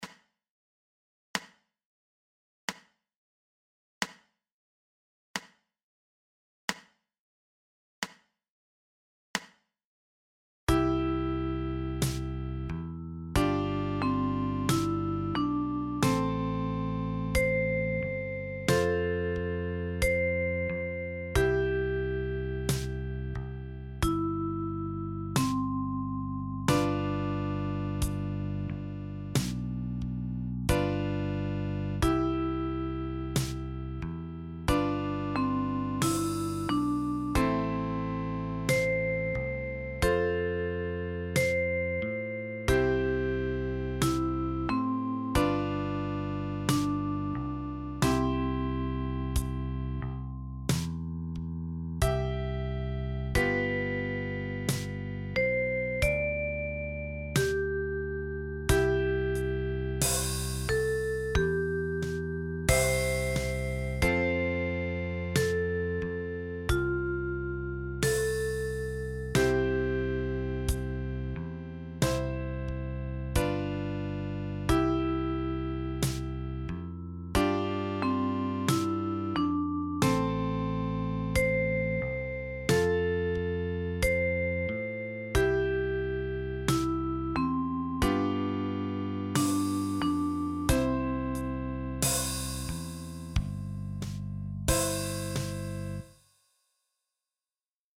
für die Kalimba mit 17 Stimmzungen